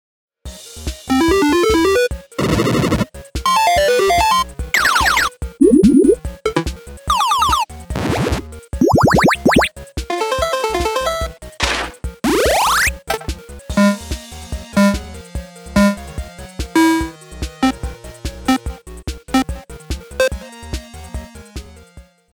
8bit sounds.mp3